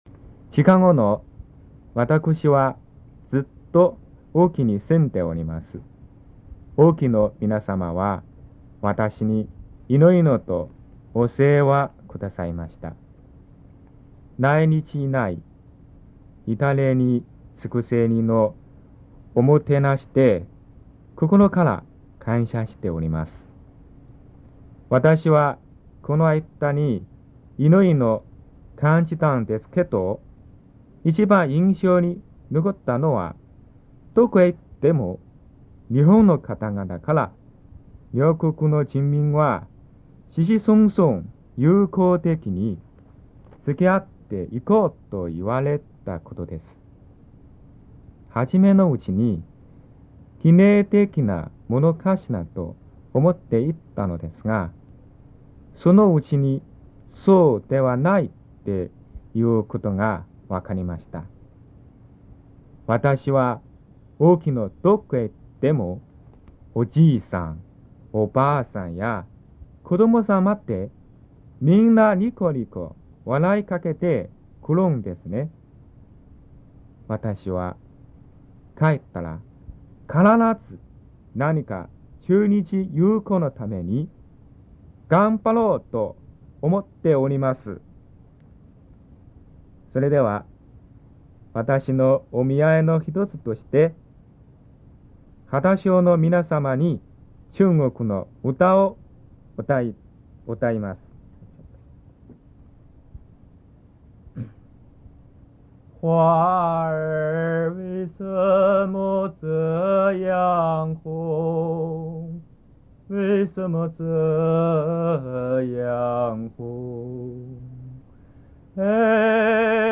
「秦荘のみなさまに」というところに、有線放送ならではの、語り手と聞き手の関係が表れています。